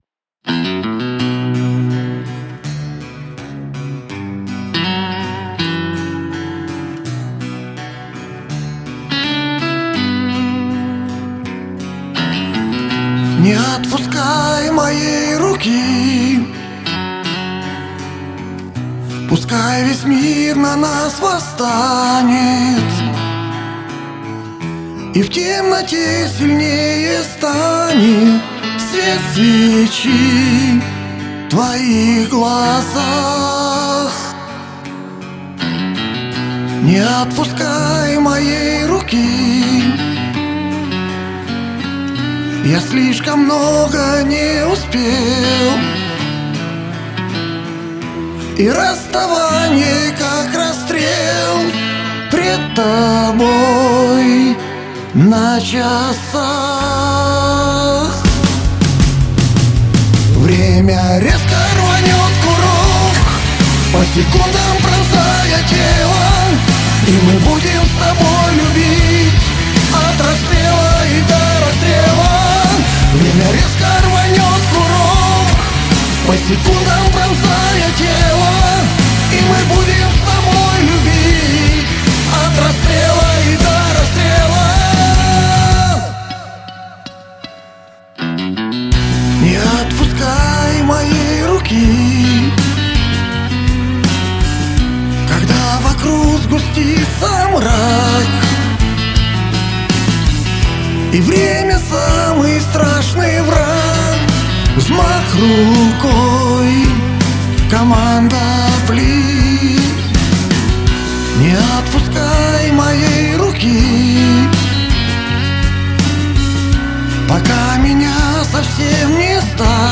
с такой вкусной хрипотцой и высокими надрывными нотами.